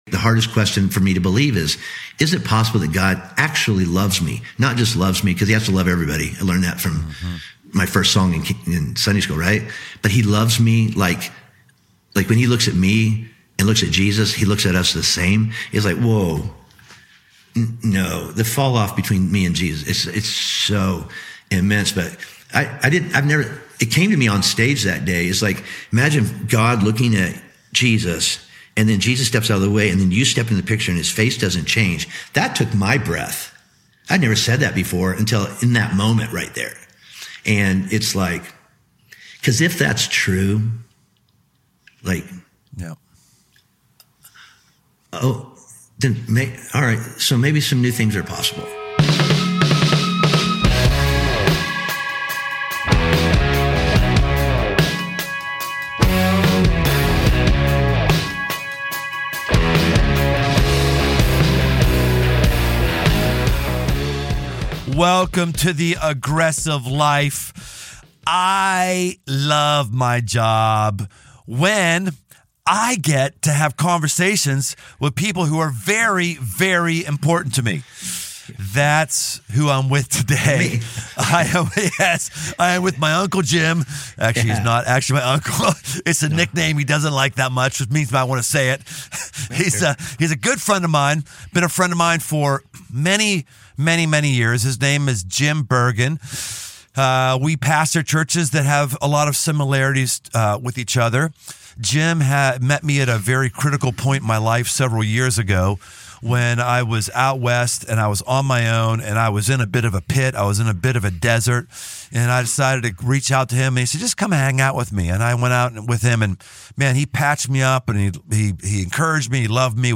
From elk hunts, to hard workouts, to spiritual warfare, this is a no-holds-barred conversation about becoming the kind of man the world needs.